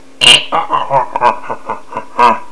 Puedes escuchar en vivo al chupacabras [audio/wav 40kb]: